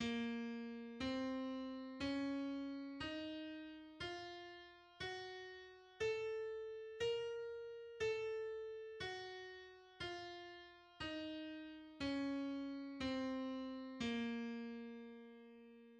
The B-flat harmonic minor and melodic minor scales are: